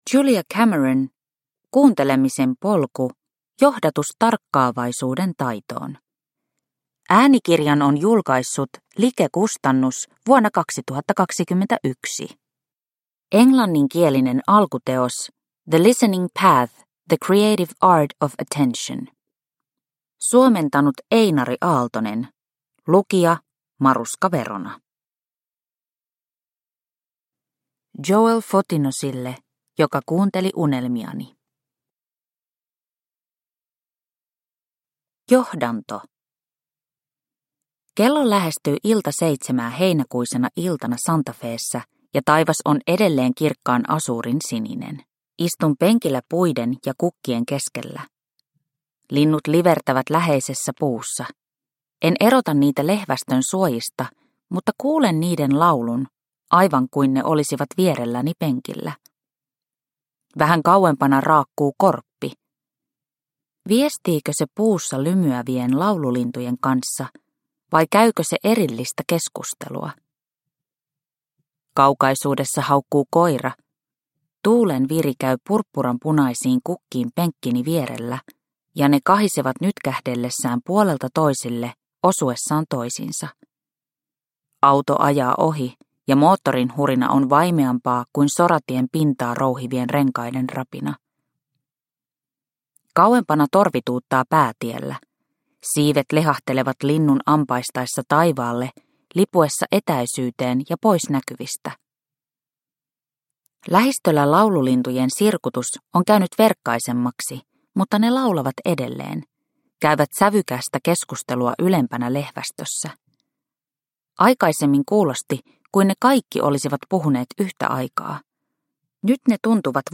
Kuuntelemisen polku – Ljudbok – Laddas ner